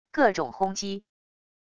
各种轰击wav音频